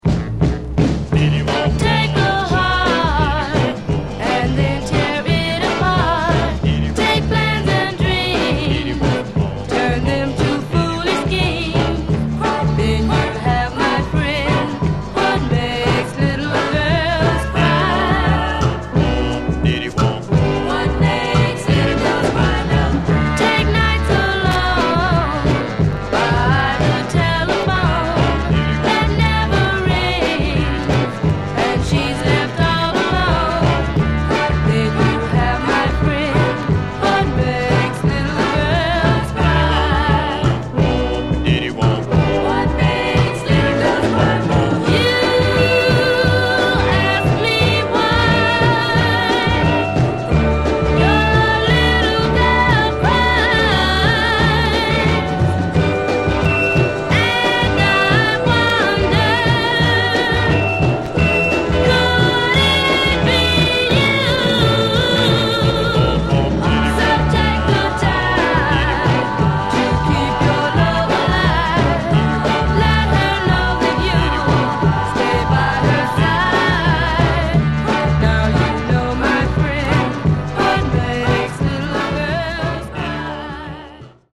Genre: Girl Group